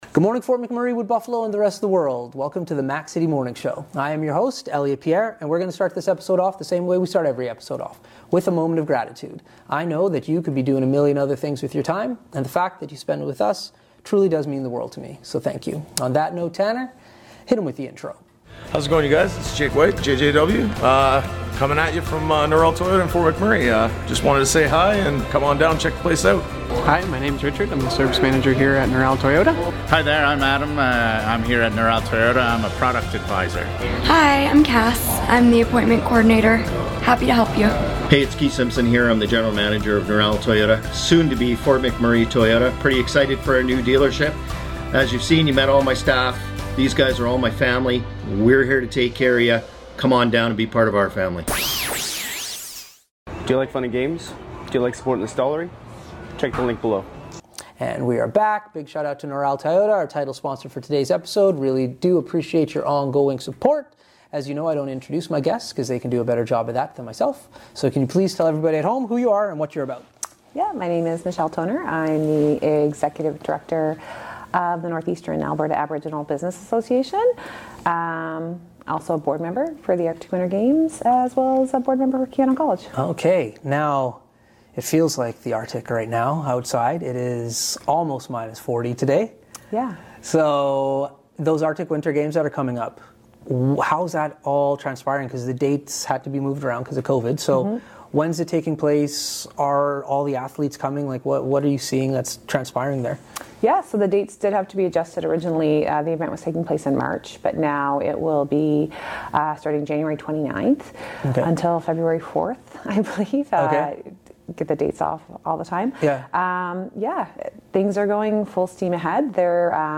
We are on location at NAABA